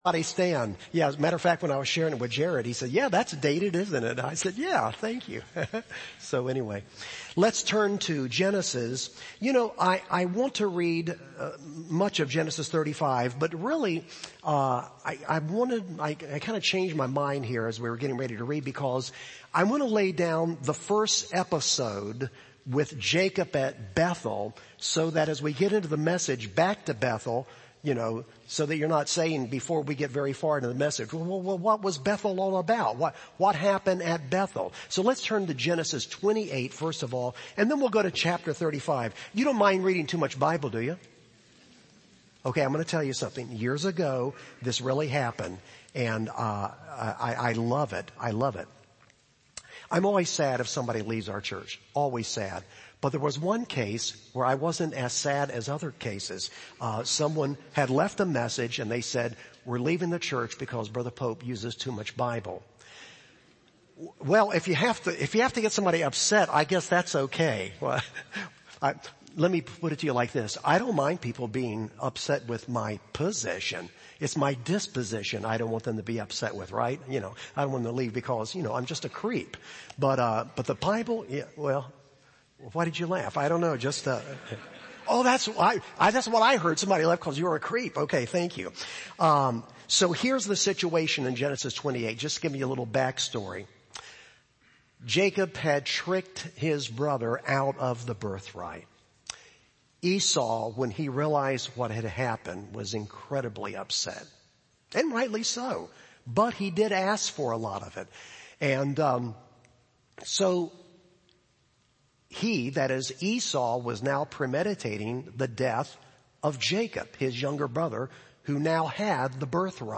Sermons Podcast - Back to Bethel - AM | Free Listening on Podbean App